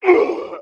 fall_2.wav